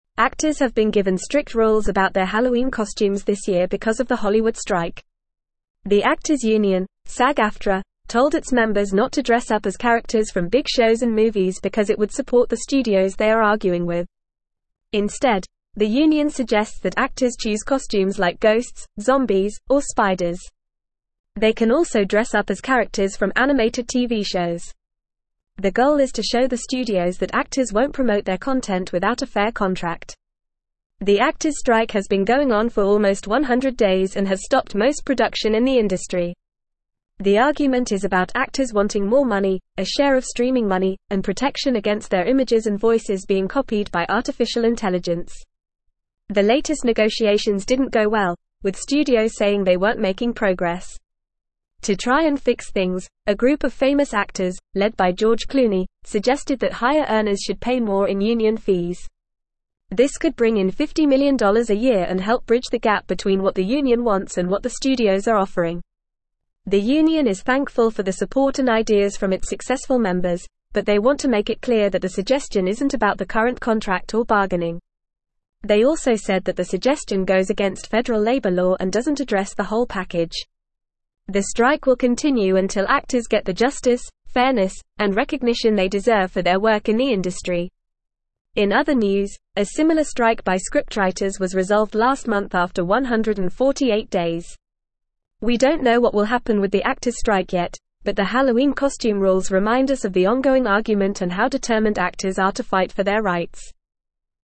Fast
English-Newsroom-Upper-Intermediate-FAST-Reading-Hollywood-Actors-Given-Halloween-Costume-Rules-Amid-Strike.mp3